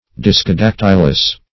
Search Result for " discodactylous" : The Collaborative International Dictionary of English v.0.48: Discodactylous \Dis`co*dac"tyl*ous\, a. (Zool.) Having sucking disks on the toes, as the tree frogs.